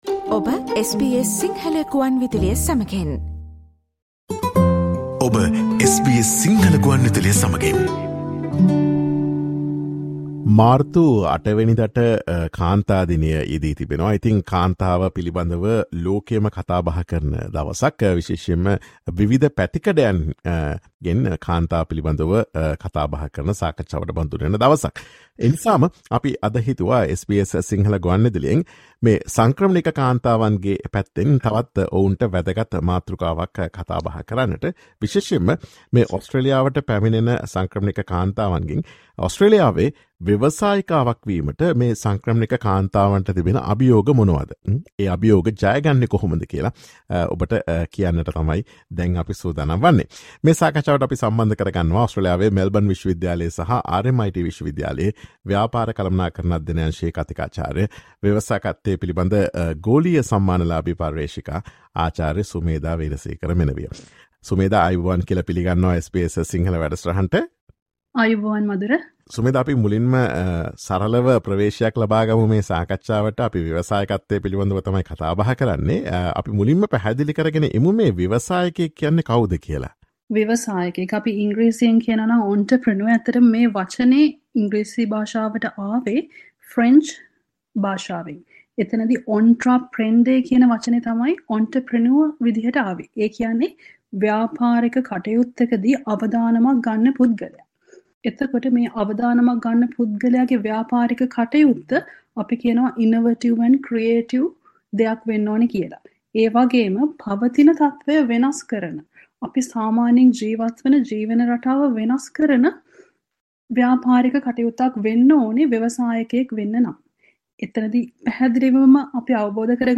Listen to SBS Sinhala Radio's discussion on the challenges migrant women face in becoming entrepreneurs in Australia and how they can overcome them.